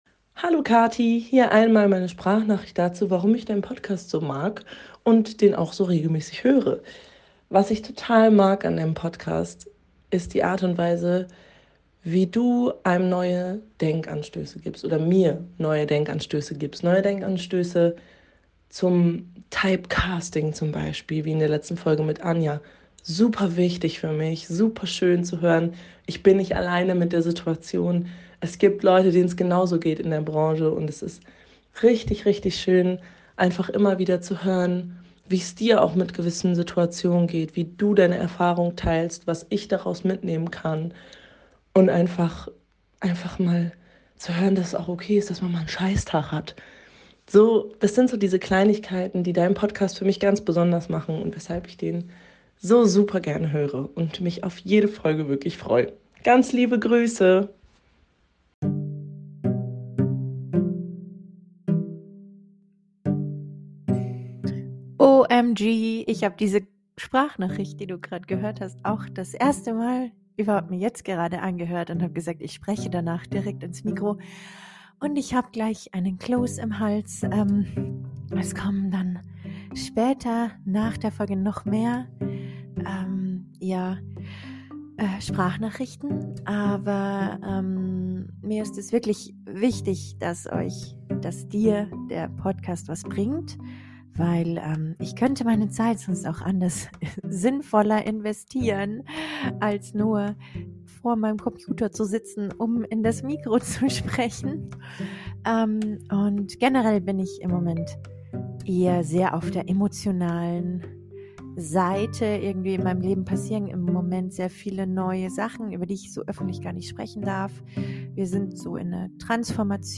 Außerdem haben auch noch ein paar von euch ganz liebe Sprachnachrichten geschickt.